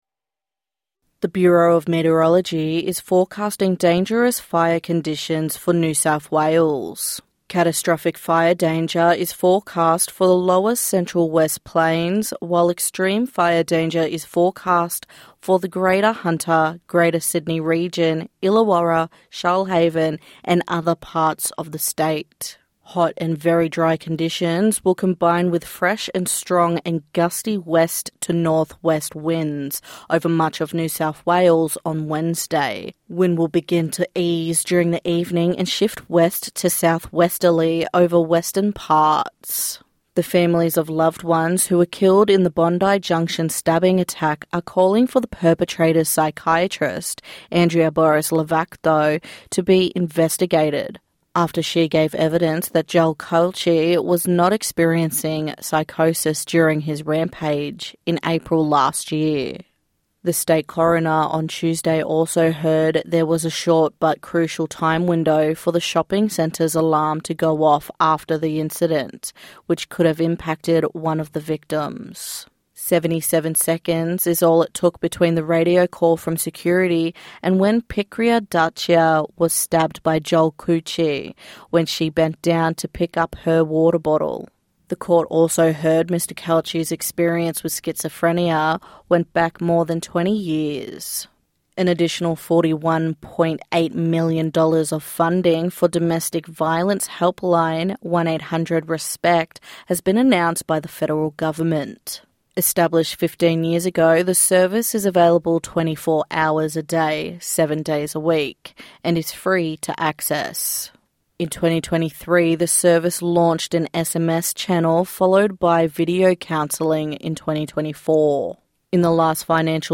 That and more in news, sport, and weather on NITV Radio.